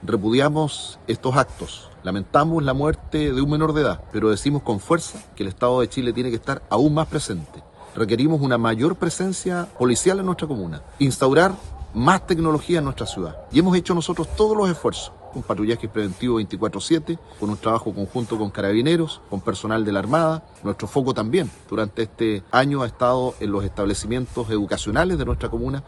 Mientras que el alcalde de Lota, Jaime Vásquez, espera que el Estado de Chile incremente su presencia con mayor tecnología y despliegue policial en la comuna a raíz de estos últimos hechos de violencia.
alcalde-x-adolescente.mp3